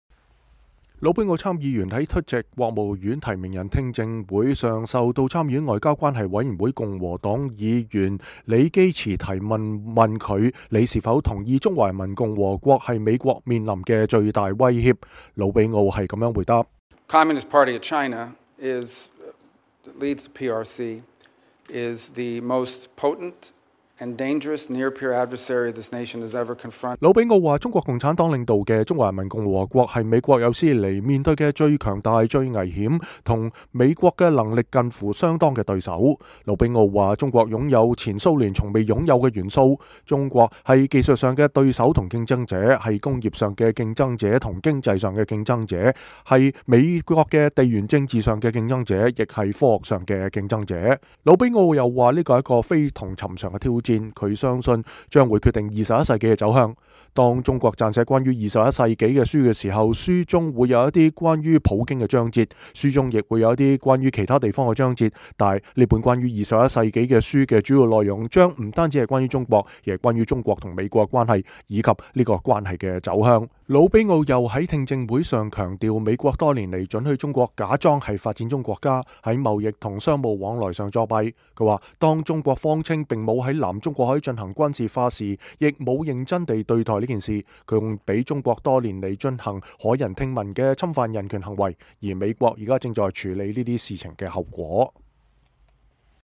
候任總統特朗普的國務卿提名人魯比奧1月15日在確認聽證會上指出，美國多年來允許中國假裝是發展中國家，在貿易和商務往來上作弊， 當中國謊稱沒有在南中國海進行軍事化時也不認真對待此事，還讓中國多年來進行駭人聽聞的侵犯人權行為，而美國現在正在處理這些事情的後果。